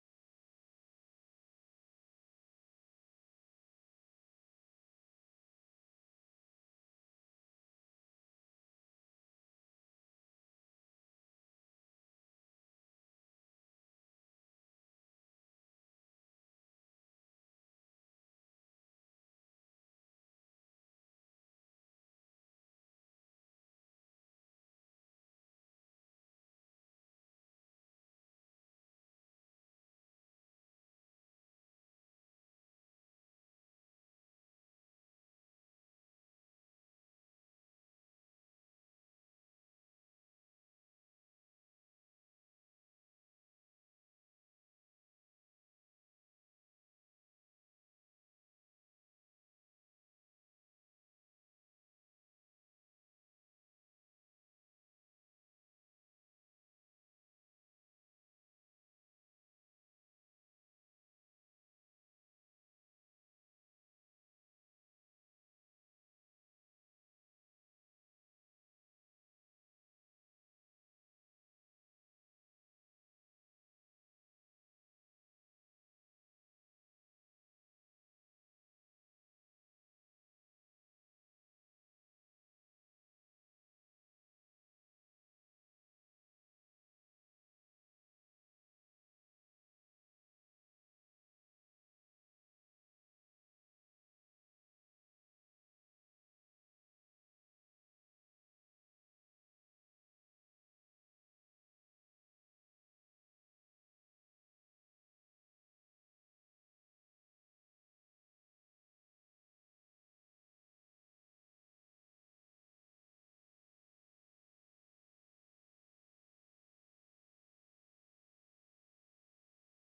The 4th of May 2025 Praise and Worship at FWC
The-4th-of-May-2025-Praise-and-Worship-at-FWC-Audio-CD.mp3